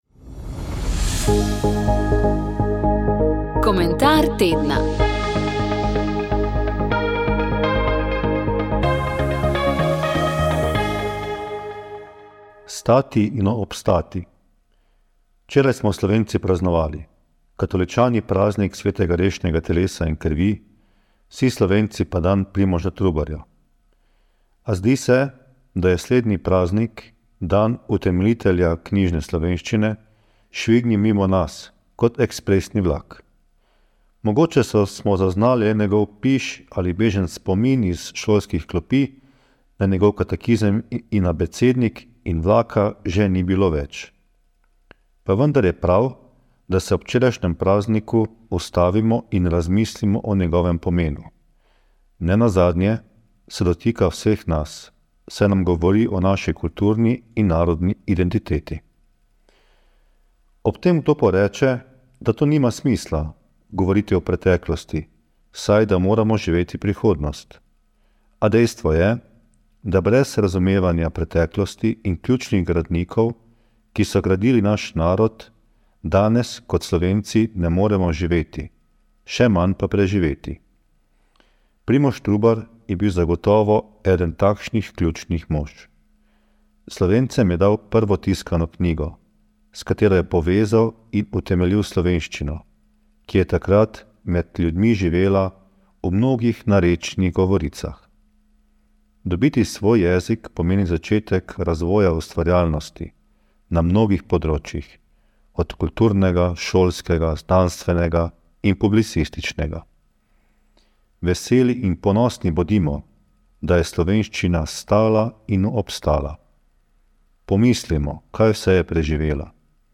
Komentar tedna